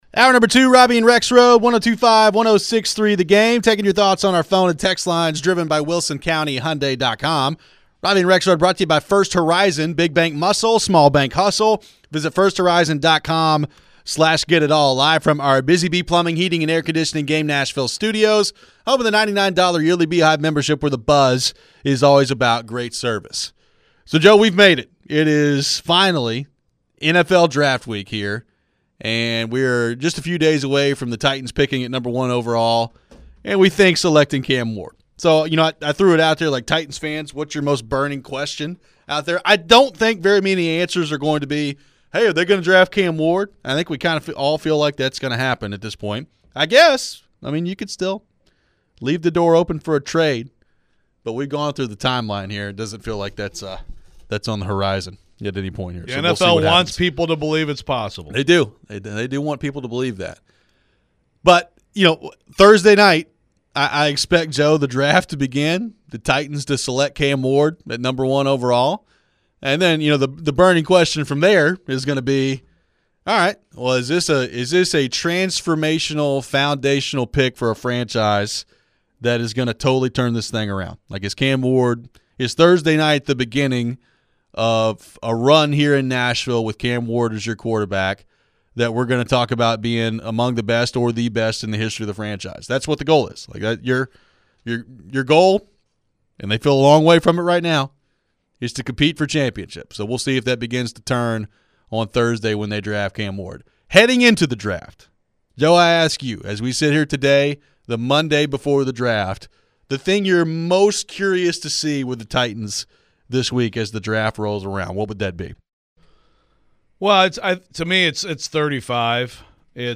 Headliner Embed Embed code See more options Share Facebook X Subscribe It is officially NFL Draft week as the 1st round starts Thursday night. What are we hearing about the latest on the Titans? We head to your phones and what about the Simmons trade rumors? What could some of the other AFC South teams be trying to target?